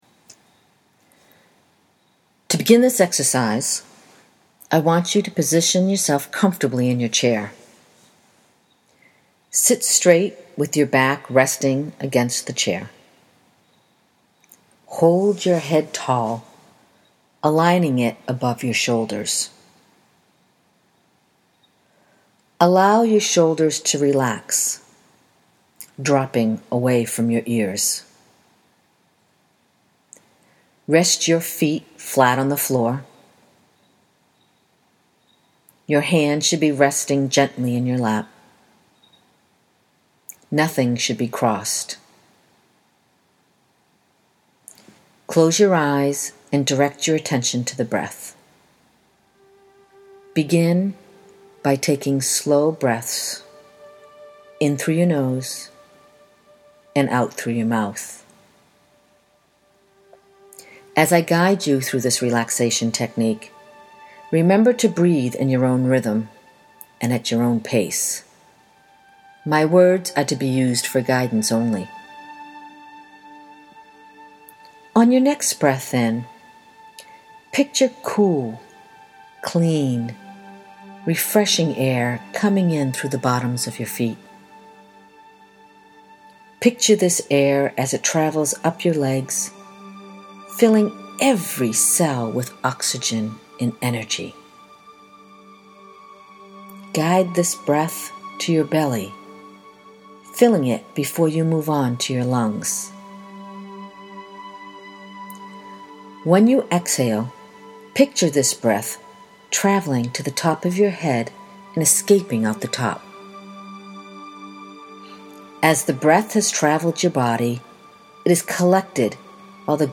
Guided Stress Meditation - Audio Only
Guided_Stress_Reduction.mp3